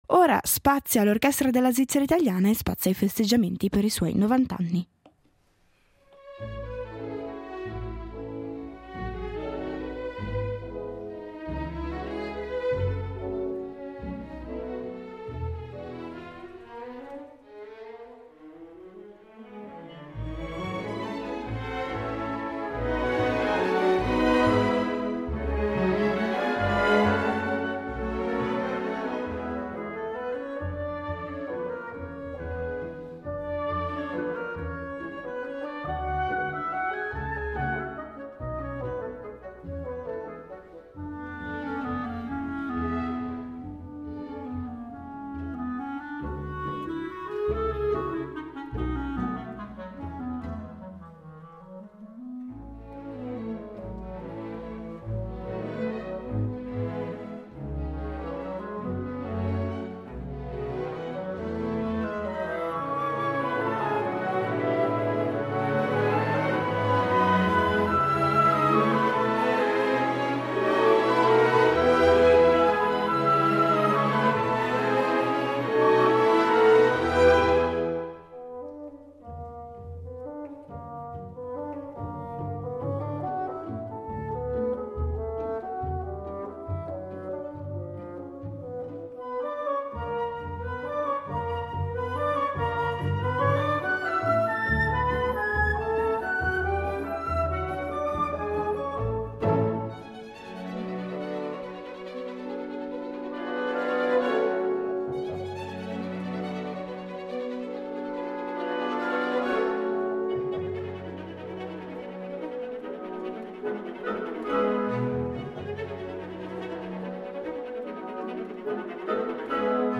Una serie di interviste per raccontare l’Orchestra della Svizzera italiana